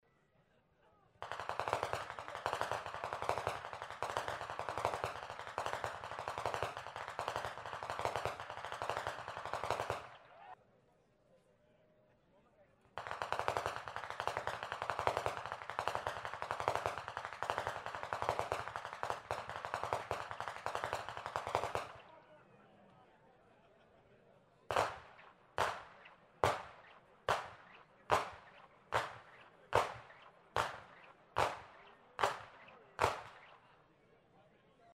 50. Ainringer Gemeindepreisschnalzen in Perach am 02.02.2025